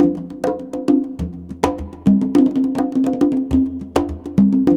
CONGBEAT12-R.wav